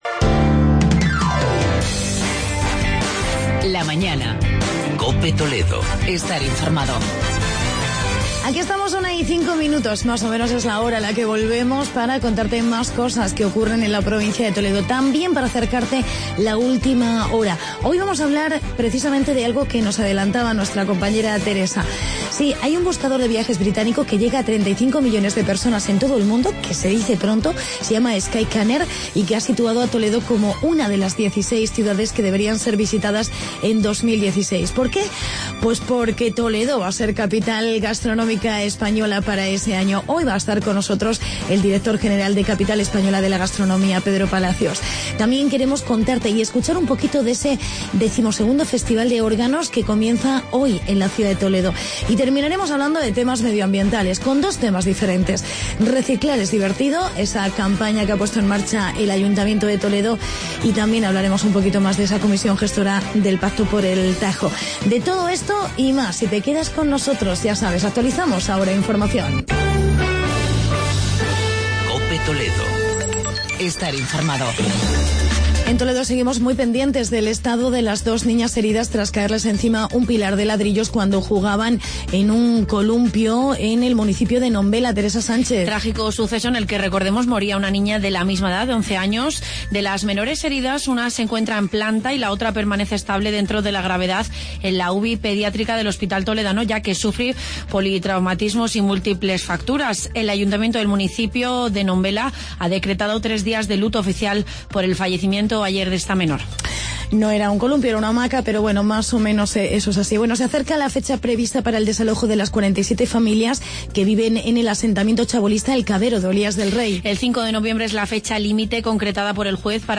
COPE TALAVERA